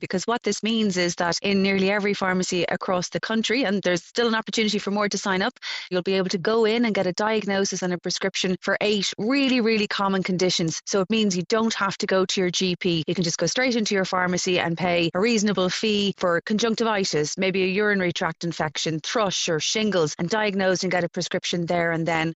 Health Minister Jennifer Carroll MacNeill says it’s a no brainer, particularly for patients: